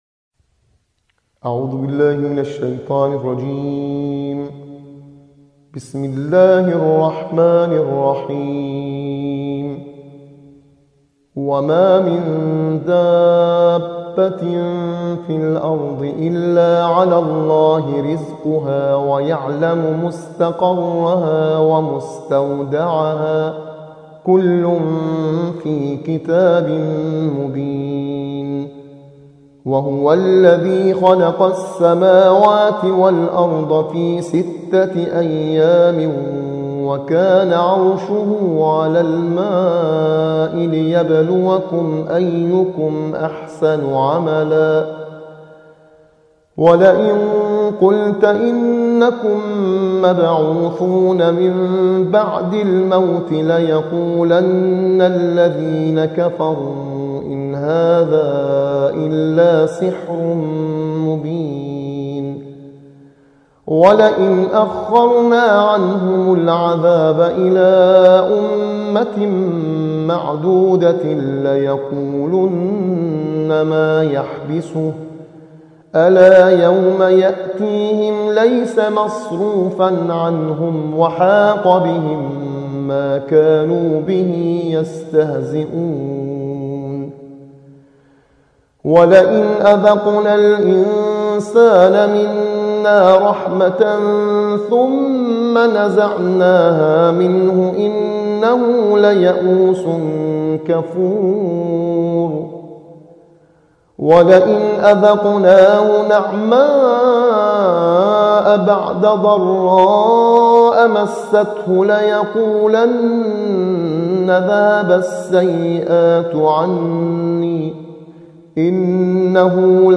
صوت | ترتیل‌خوانی جزء دوازدهم قرآن + آموزش نغمات